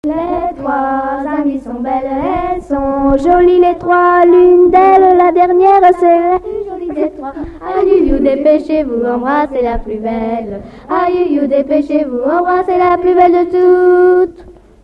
Rondes à baisers et à mariages fictifs
danse : ronde à embrasser
Pièce musicale inédite